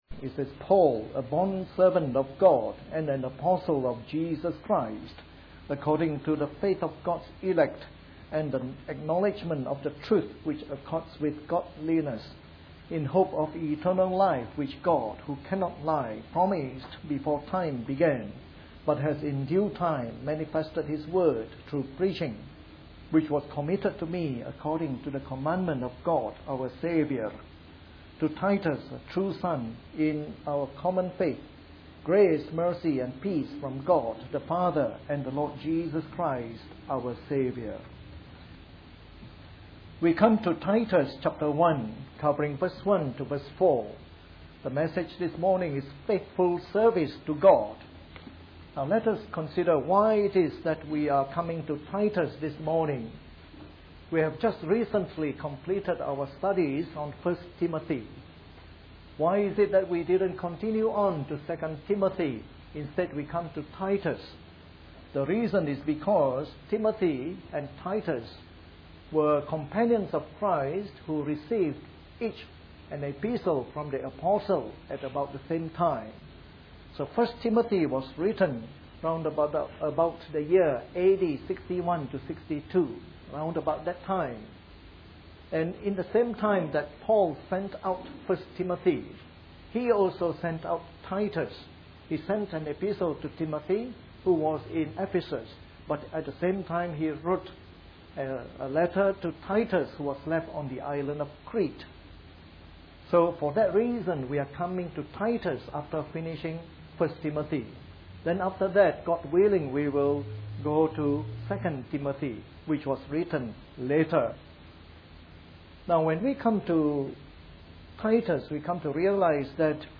A sermon in the morning service from our new series on Titus.